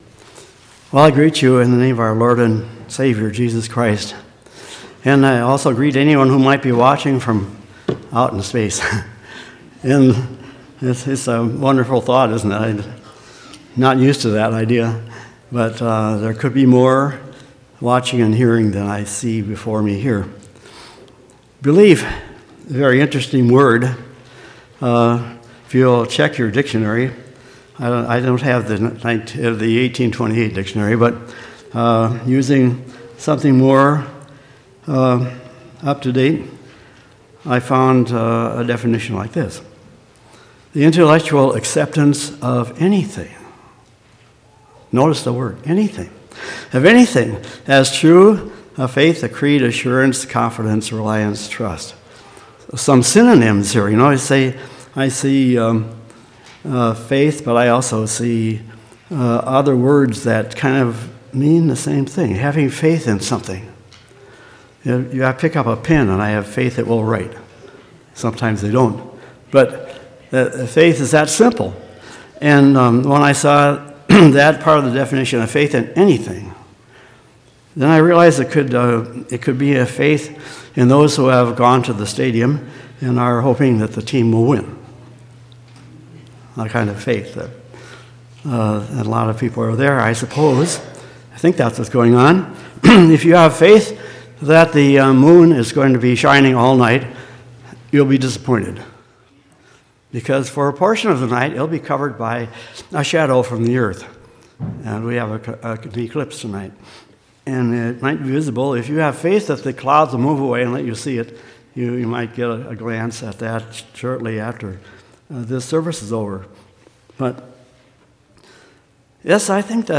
1/20/2019 Location: Temple Lot Local Event